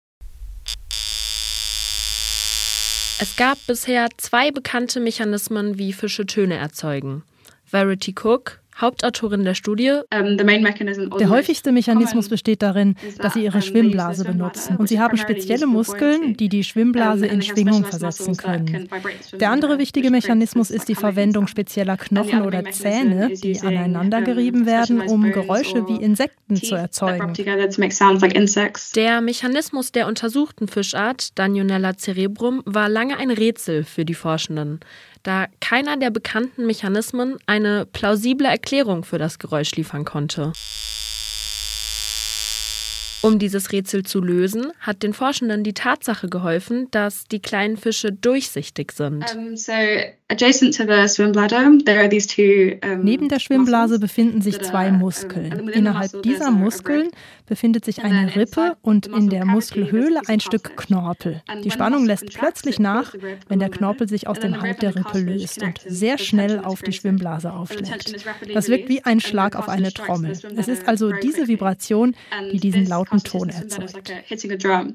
So laut, wie man einen startenden Düsenjet aus rund 100 Metern Entfernung hört, sagen die Forscher, die ihn untersucht haben: 140 Dezibel. Den Lärm veranstaltet er wahrscheinlich, um Artgenossen im trüben Wasser auf sich aufmerksam zu machen.
Nachrichten So klingt der Lärm des Danionella
Aneinandergereiht erzeugen diese Impulse Rufe.